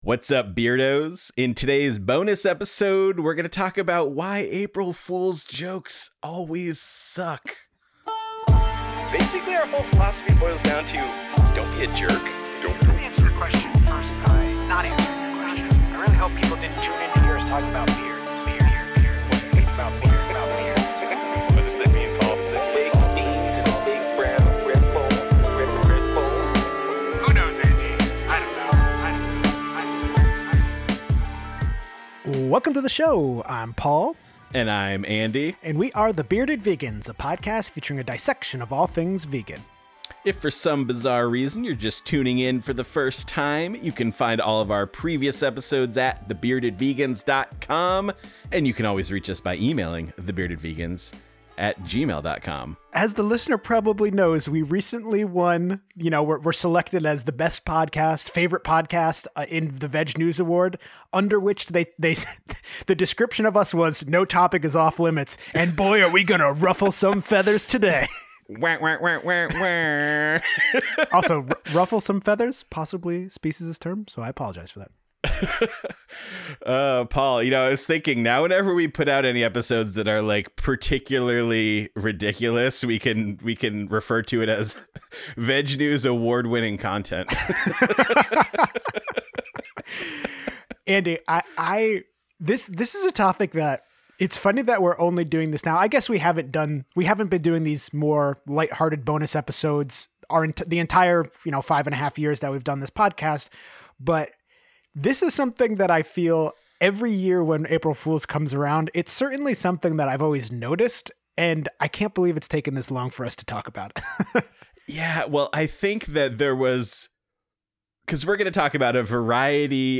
Do you love it when two guys try to dissect and intellectualize humor?